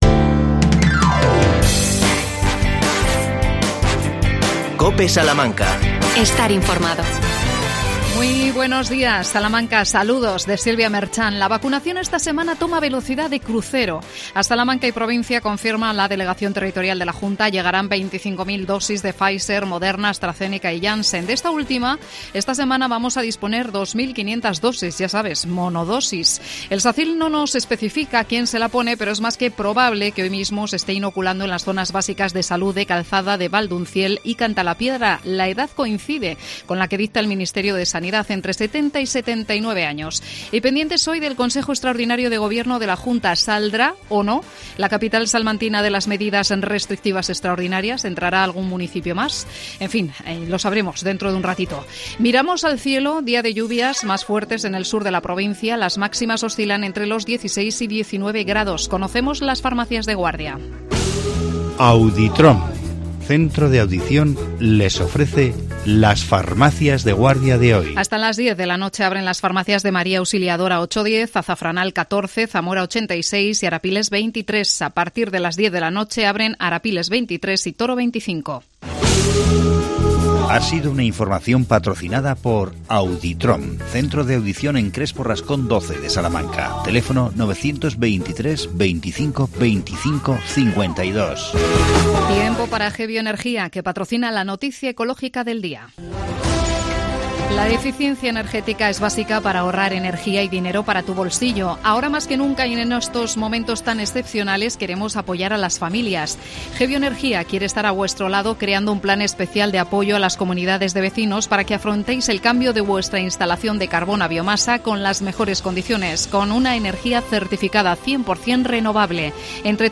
AUDIO: Entrevista al presidente de la Diputación, Javier Iglesias.Primer fin de semana del Camino de Hierro en activo.